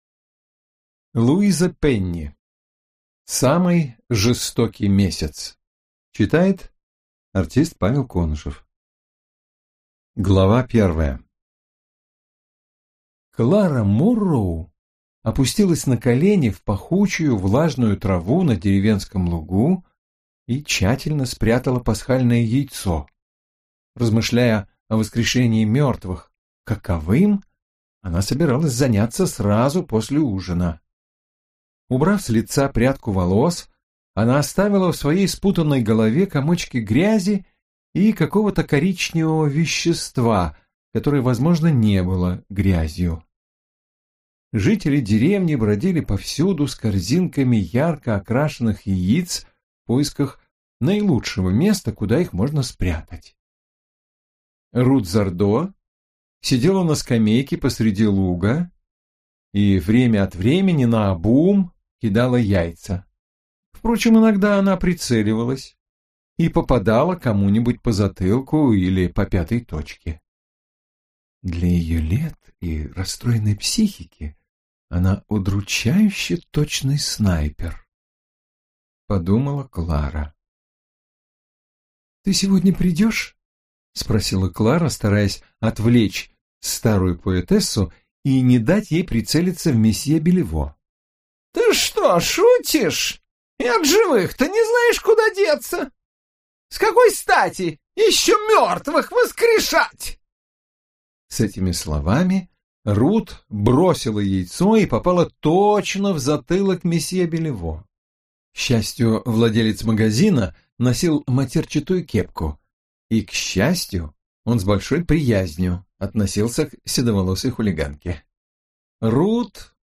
Аудиокнига Самый жестокий месяц - купить, скачать и слушать онлайн | КнигоПоиск